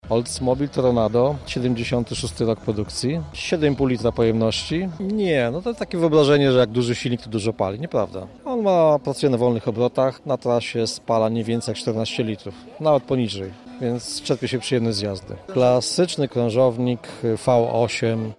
Zlot klasycznych aut zorganizowano przy galerii handlowej przy ul. Spółdzielczości Pracy w Lublinie.